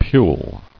[pule]